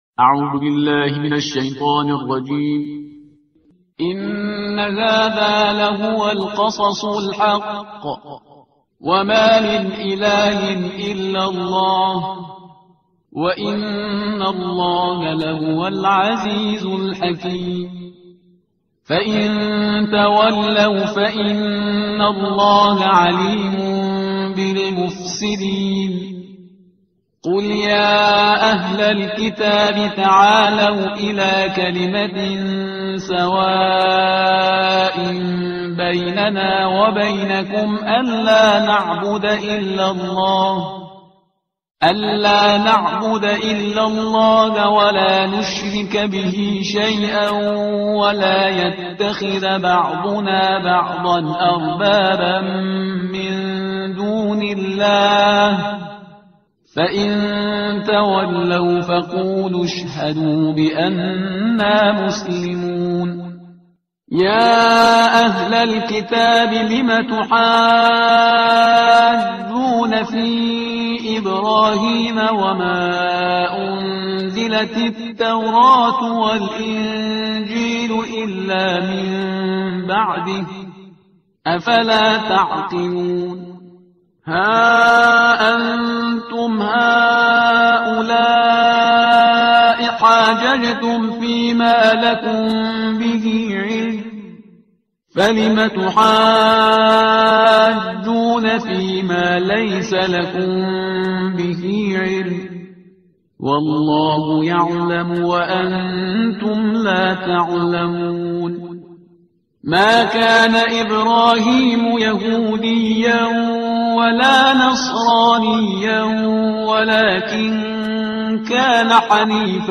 ترتیل صفحه 58 قرآن